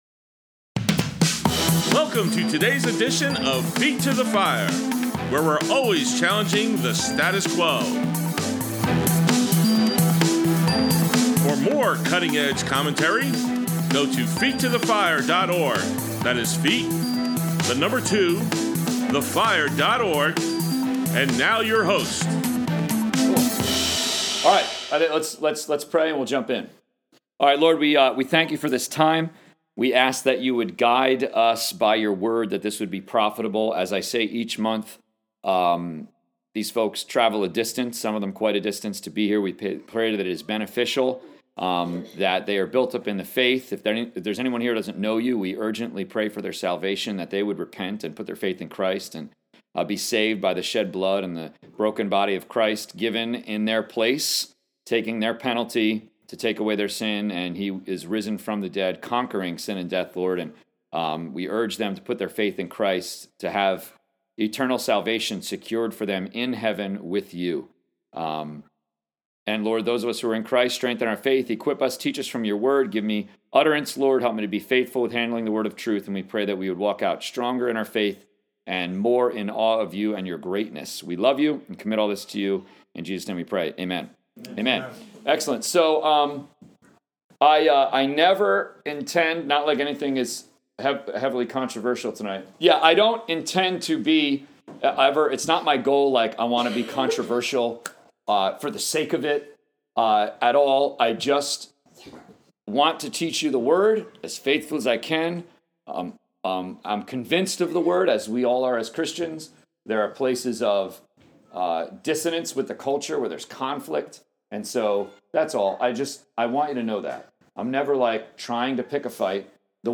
Saturday Sermons 9.17.26: 1 Peter 3:8-12 A Biblical Understanding of Compassion, Part 1